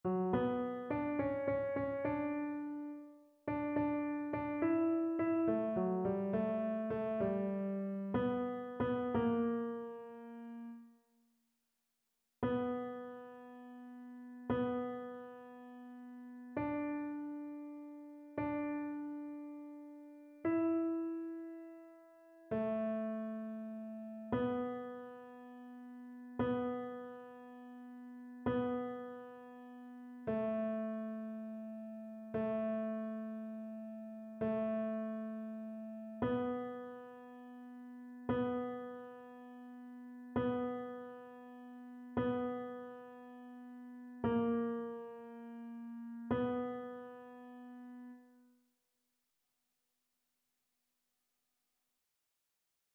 Ténor
annee-c-temps-de-l-avent-3e-dimanche-cantique-d-isaie-tenor.mp3